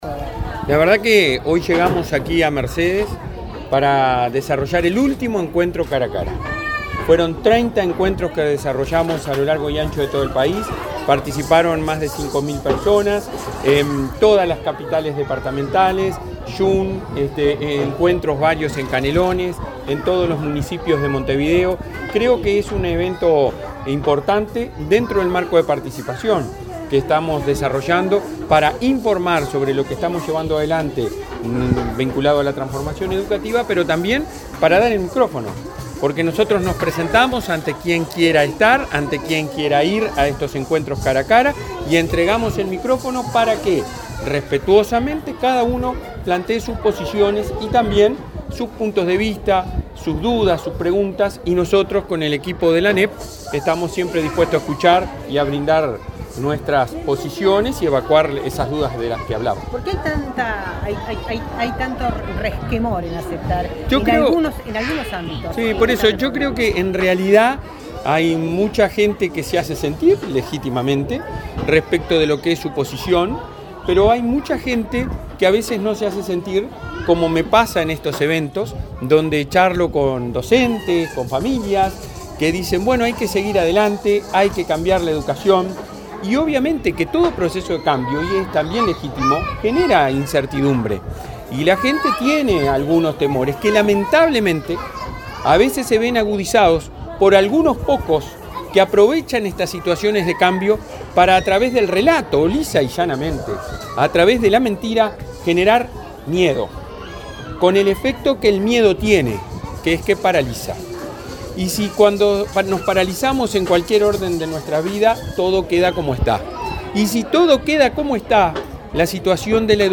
Declaraciones del presidente de la ANEP, Robert Silva
El presidente de la Administración Nacional de Educación Pública (ANEP), Robert Silva, dialogó con la prensa en Mercedes, Soriano, donde este viernes